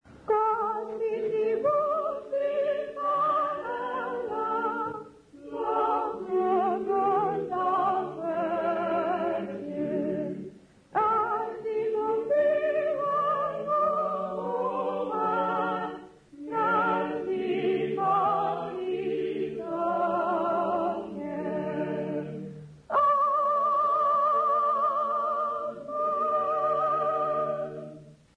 Intshanga church music workshop participants
Sacred music South Africa
Folk music South Africa
Hymns, Zulu South Africa
field recordings
Unaccompanied offertory church hymn.